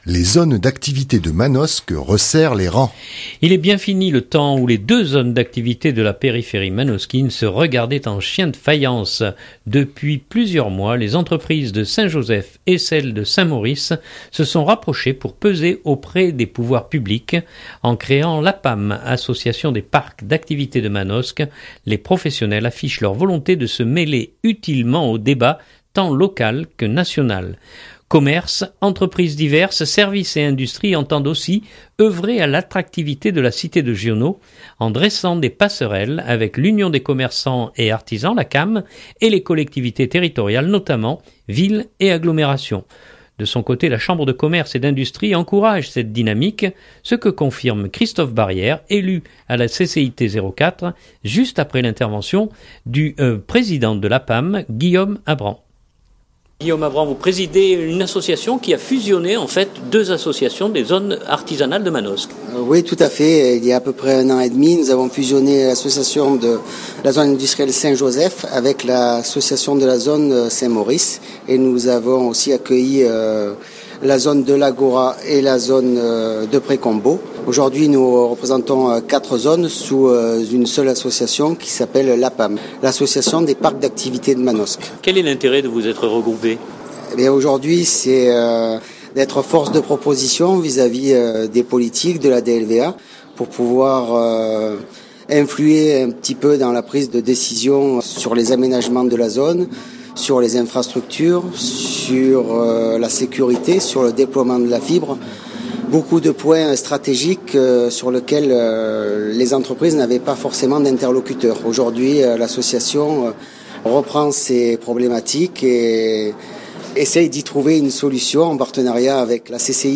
reportage-APAM.mp3 (4.22 Mo) Il est bien fini le temps où les deux zones d’activités de la périphérie manosquine se regardaient en chiens de faïence. Depuis plusieurs mois, les entreprises de St Joseph et celles de St Maurice se sont rapprochées pour peser auprès des pouvoirs publics. En créant l’APAM, association des parcs d’activités de Manosque, les professionnels affichent leur volonté de se mêler utilement au débat tant local que national.